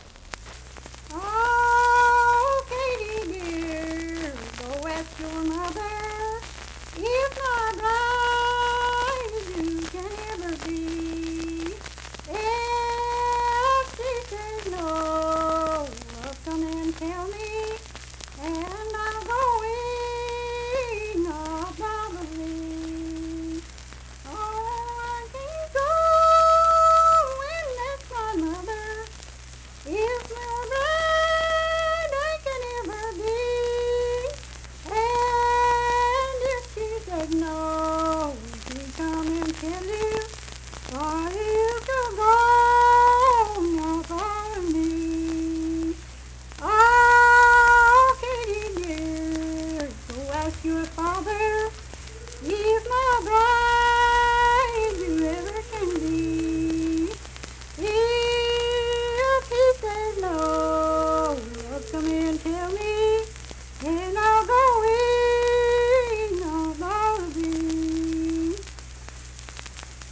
Unaccompanied vocal music performance
Voice (sung)
Kirk (W. Va.), Mingo County (W. Va.)